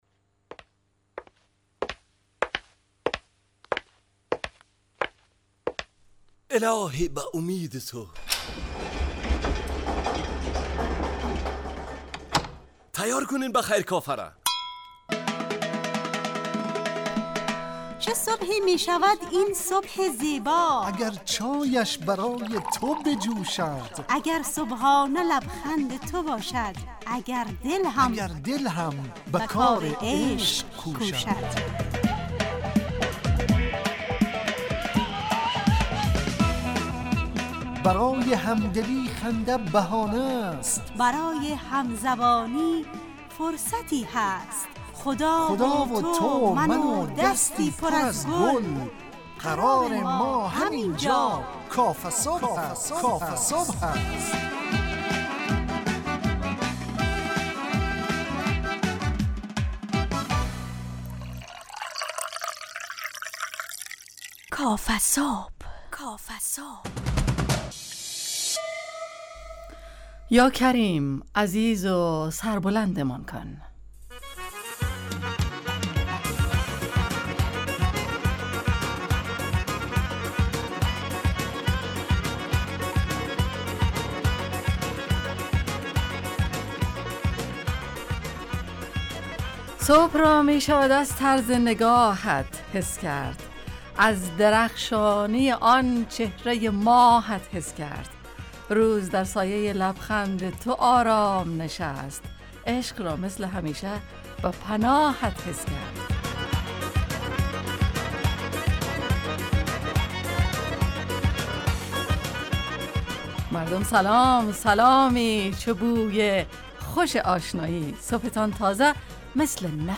کافه‌صبح – مجله‌ی صبحگاهی رادیو دری با هدف ایجاد فضای شاد و پرنشاط صبحگاهی
با بخش‌های کارشناسی، نگاهی به سایت‌ها، گزارش، هواشناسی٬ صبح جامعه، گپ صبح و صداها و پیام‌ها شنونده‌های عزیز